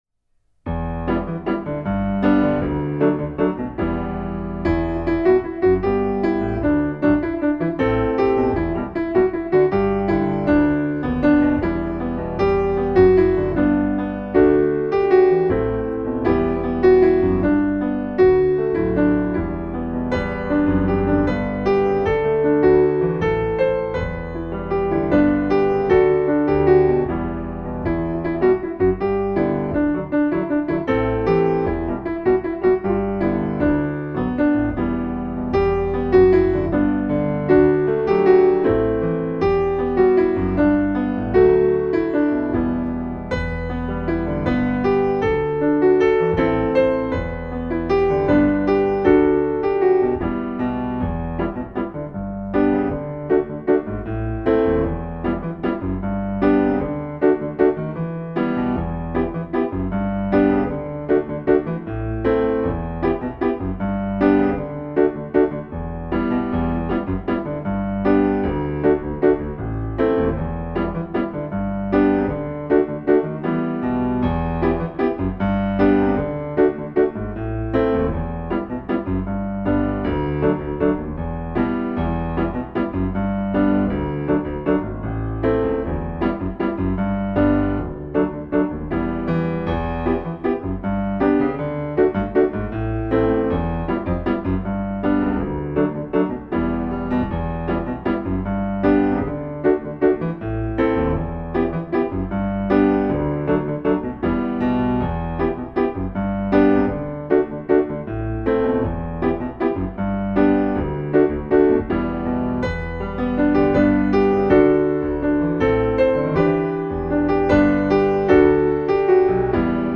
soemandsvise_piano.mp3